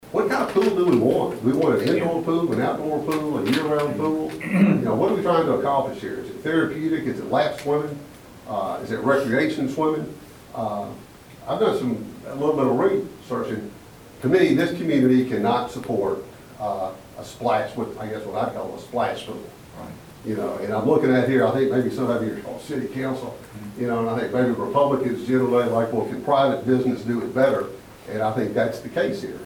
At a town hall Wednesday night, the room may have been full of residents and city officials, but only a handful voiced opinions on Bluegrass Splash, and those were split.